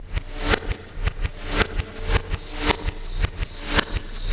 Black Hole Beat 08.wav